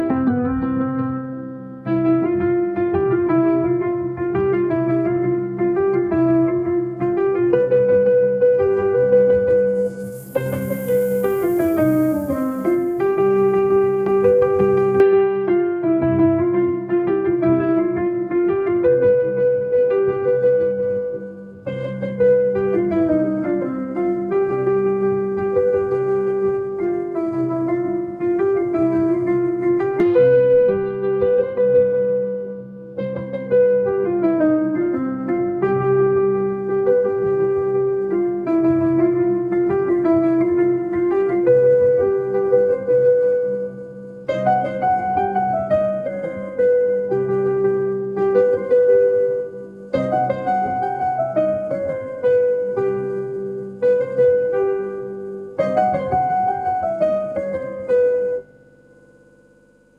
audio-to-audio music-generation